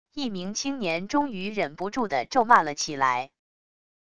一名青年终于忍不住的咒骂了起来wav音频